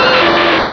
Cri_0126_DP.ogg